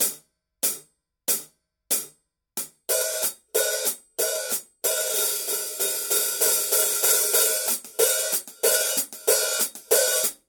These cymbals are lighter resulting in a deeper, warmer sound while preserving its bright basic character.
Overall the PST 5 is significantly more musical.